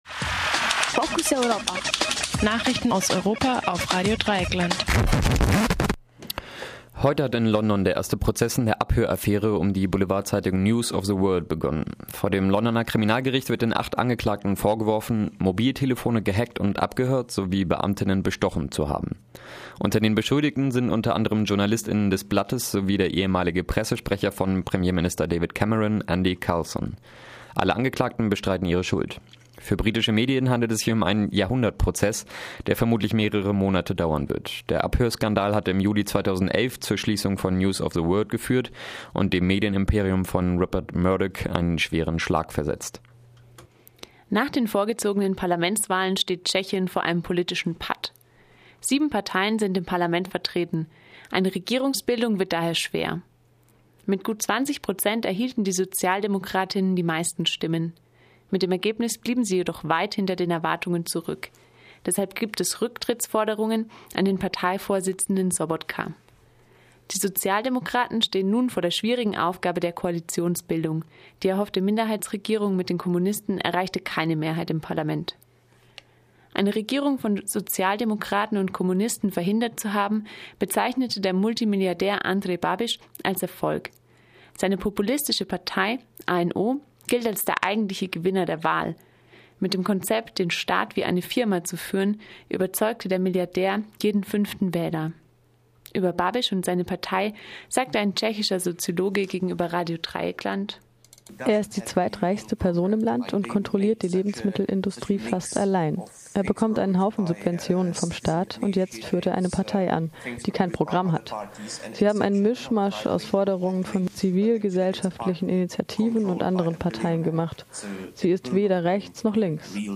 Focus Europa Nachrichten vom Montag, den 28. Oktober 2013 - 12:30 Uhr